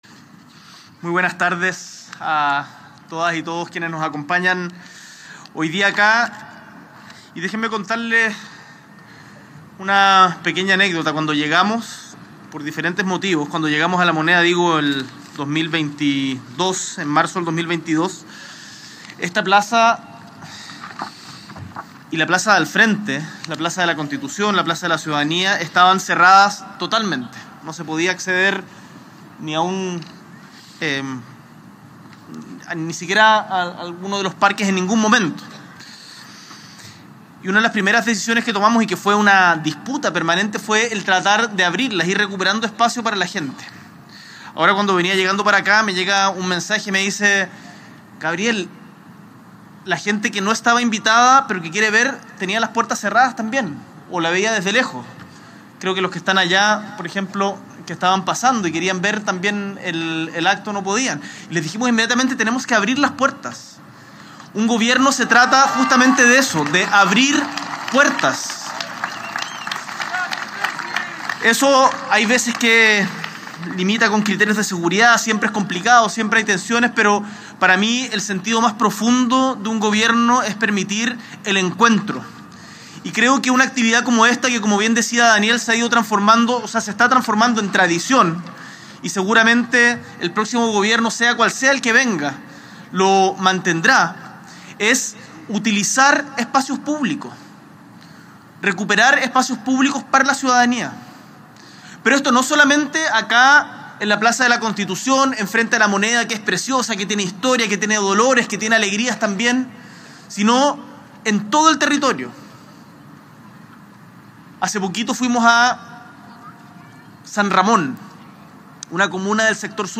S.E. el Presidente de la República, Gabriel Boric Font, encabeza la ceremonia de encendido del Árbol de Navidad BancoEstado 2024, junto a ministras y ministros, subsecretarias y subsecretarios, y al presidente de BancoEstado, Daniel Hojman.